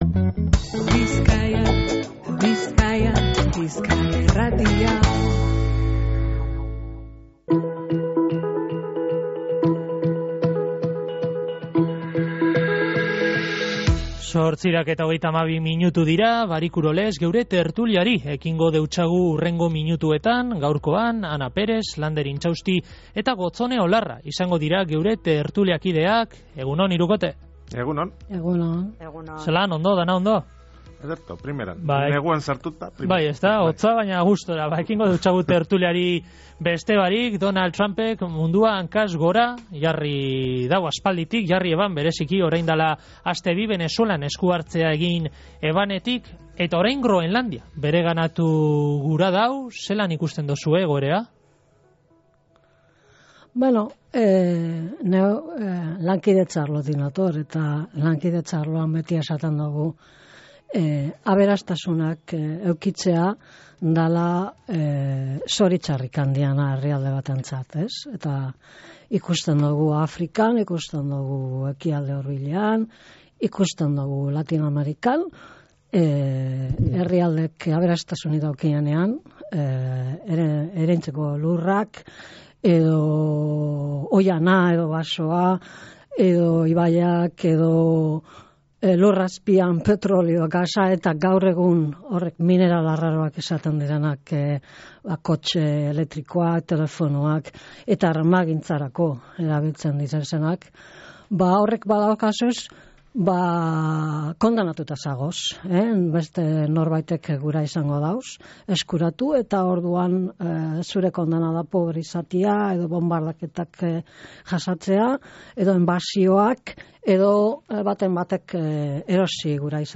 Groenlandia anexionatzeko AEBen asmoa eta Osakidetzako medikuen greba protagonista gaurko tertulian | Bizkaia Irratia